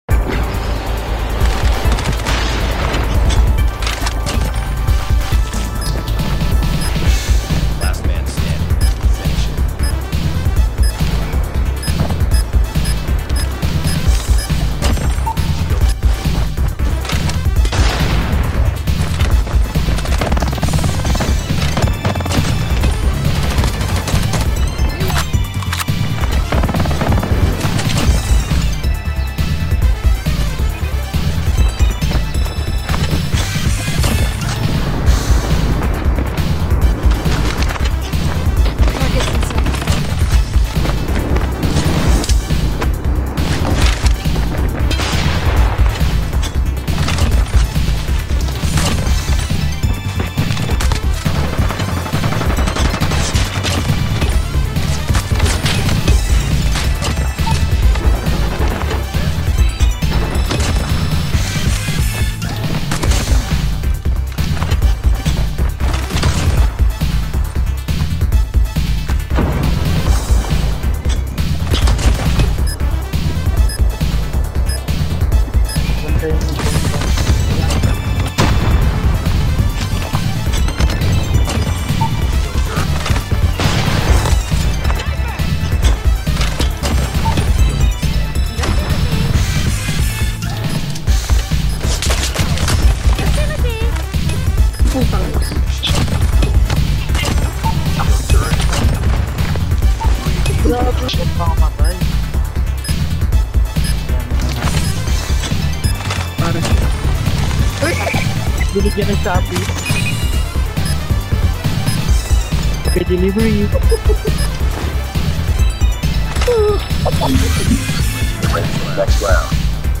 Mp3 Sound Effect my C4 kills compilation in Call of Duty Mobile